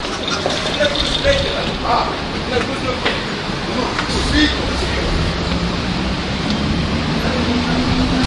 描述：希尔顿科隆酒店，户外环境
Tag: 希尔顿 希尔顿 - 结肠宾馆 酒店 酒店式的声音 出DORS